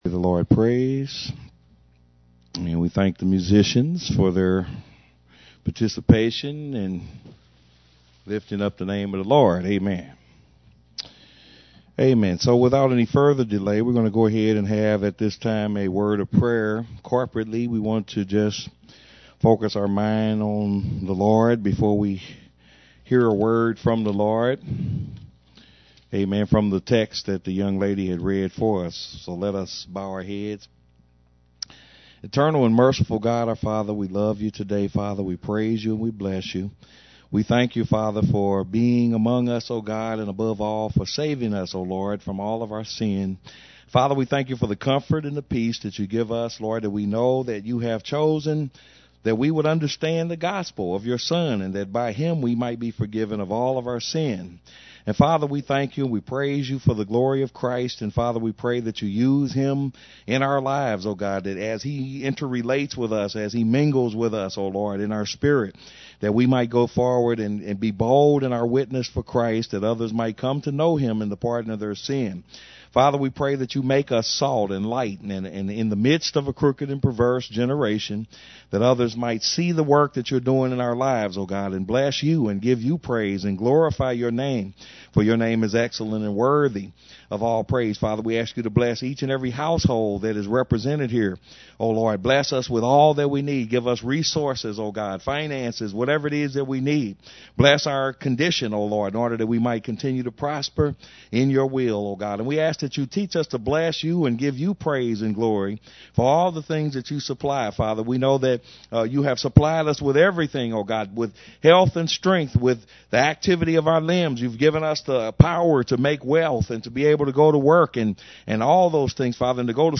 As we increase in our understanding of the things of God & our perception of His splendor & majesty develops, we find that we begin to see the LORD in a far more greater way than before day by day. Listen to this teaching as Pastor